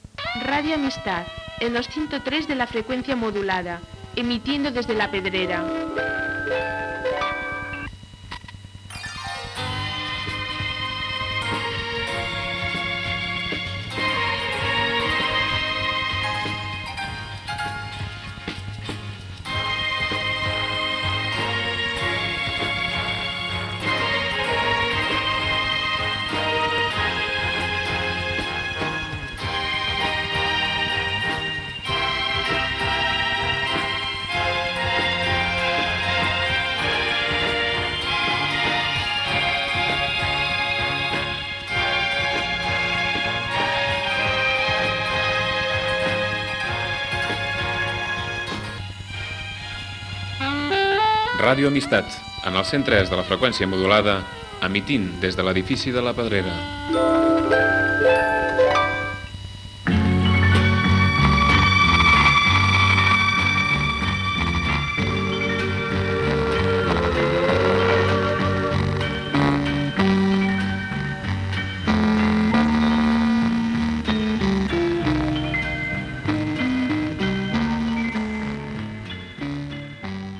5d80bdc71a02de3e54b44a7d523cedf8846c14cc.mp3 Títol Radio Amistad (Barcelona) Emissora Radio Amistad (Barcelona) Titularitat Tercer sector Tercer Sector Musical Descripció Indicatius i música.